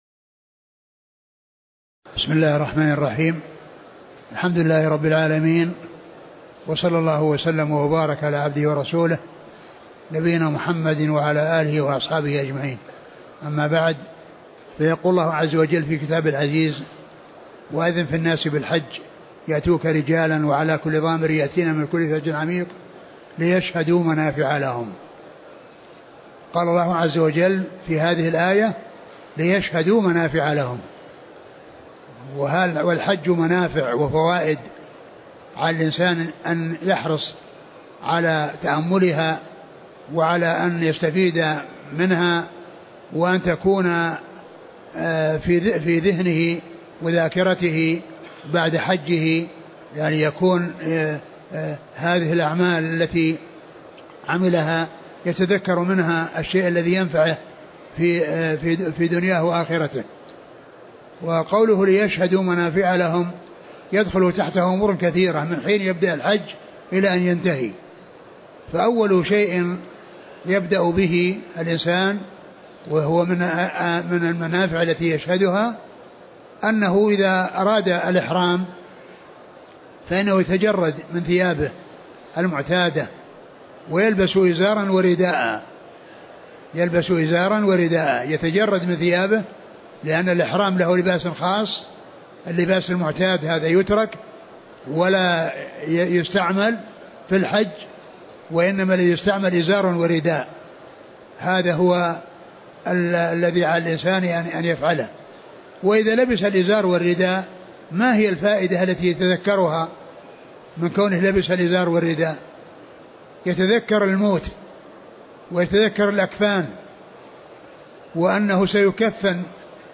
محاضرة منافع وفوائد الحج الشيخ عبد المحسن بن حمد العباد